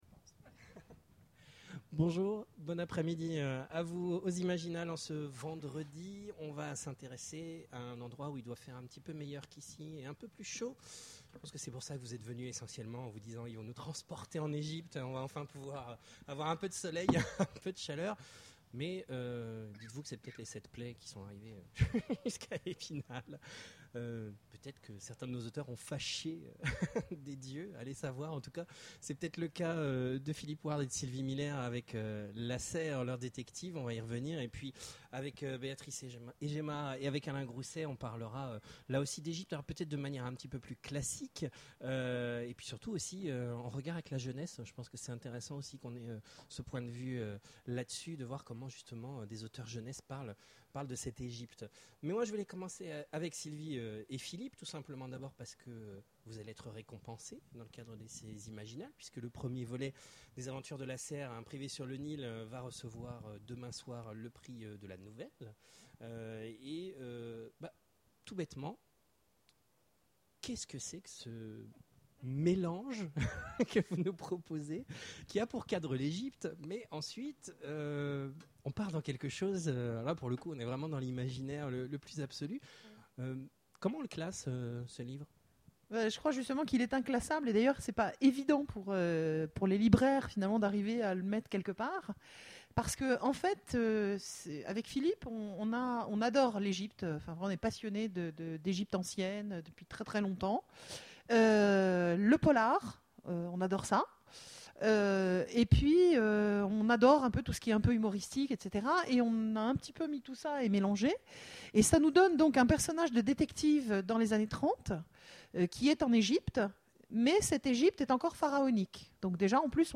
Imaginales 2013 : Conférence Voyageur temporel, privé des Dieux...